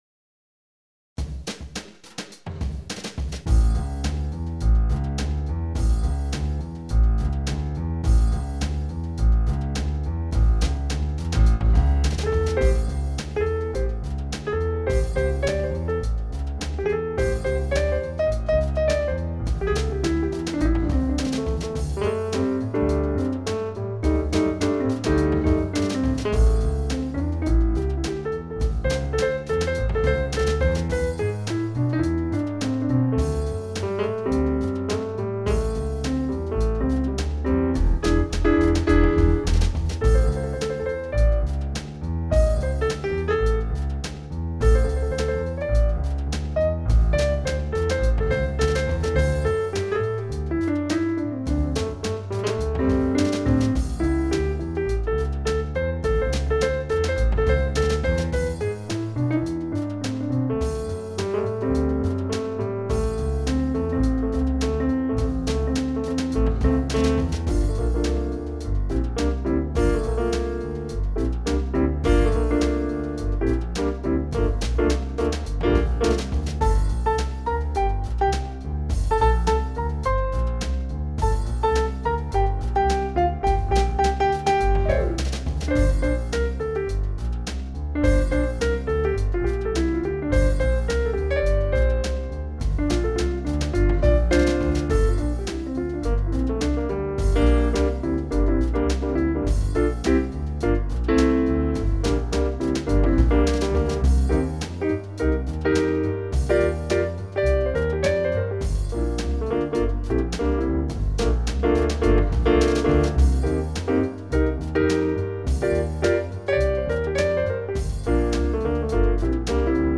8:48/105,70bpm